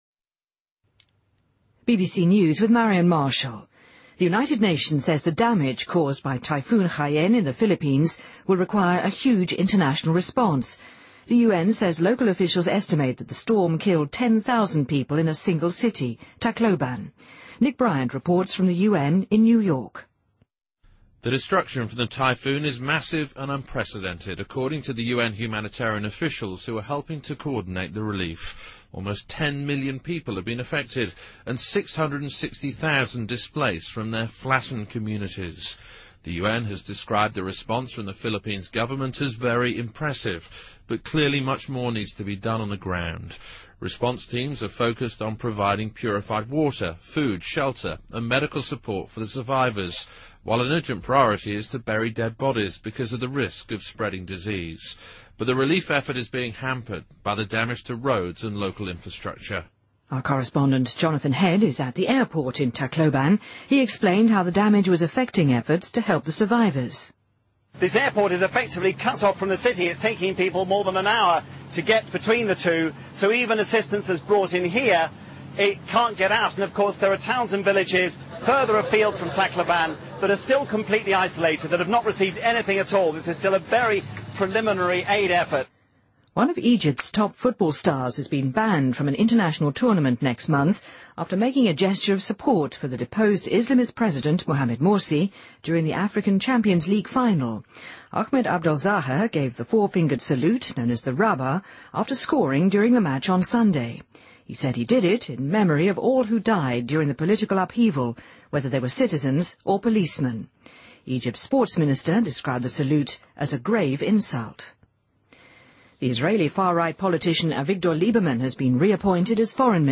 BBC news,2013-11-12